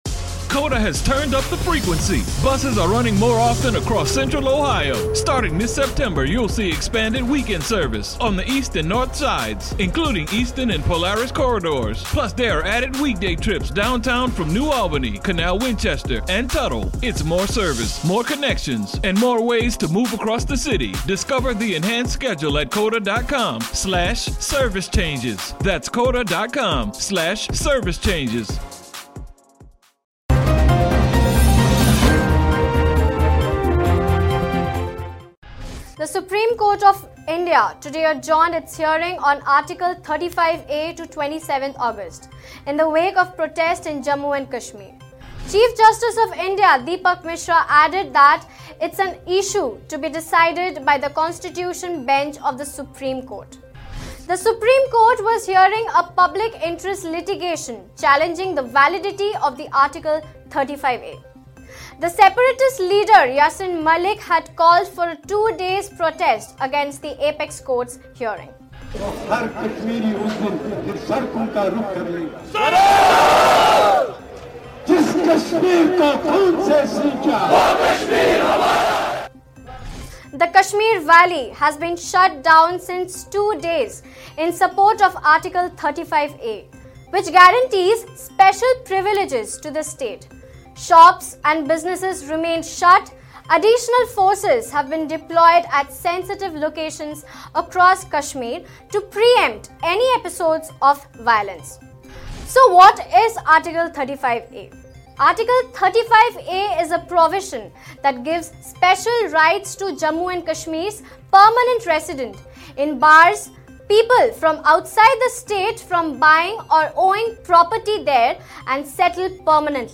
News Report / Article 35(A): SC delay the hearing on Kashmir's identity.